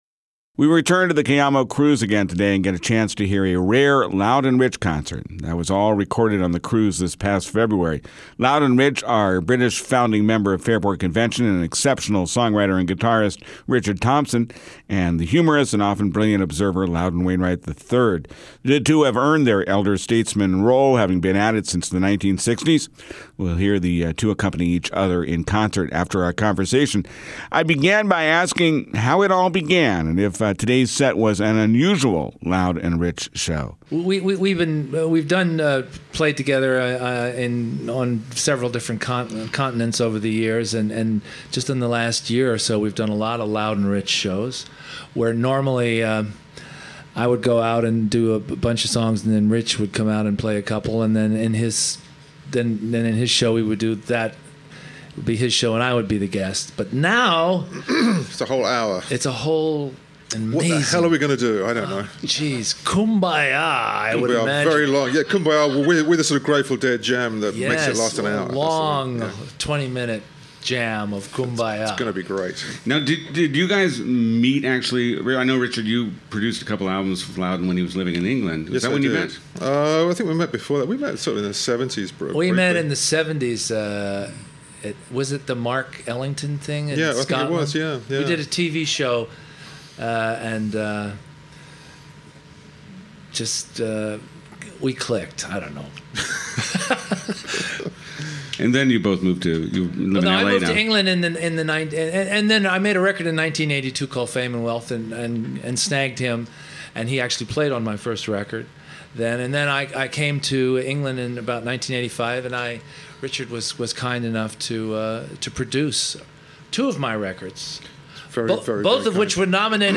The two folk-rock legends describe how they began performing with one another for the Loud and Rich Tour. Wainwright and Thompson also play songs together as part of the 2011 Cayamo Cruise.